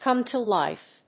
アクセントcòme to lífe